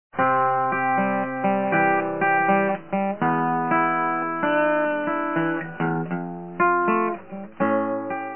［１．フィンガーピッキング］
パターン 　フィンガーピッキングの基本パターンです。
C F C G7 C F C/G7 C　循環コードによるサンプル演奏が聞けます。